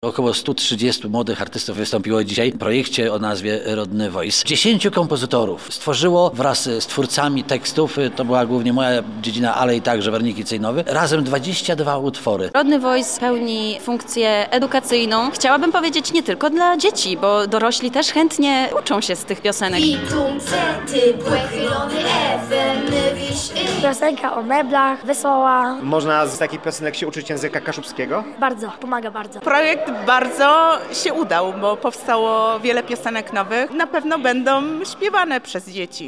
Na scenie wystąpiło prawie 130 dzieci, prezentując nowe piosenki uczące języka kaszubskiego. W Centrum Kultury Spichlerz w Żukowie odbył się koncert Rodny Voice, który podsumował projekt muzyczny łączący 14 dziecięcych zespołów z całego regionu.
Posłuchaj materiału reportera: https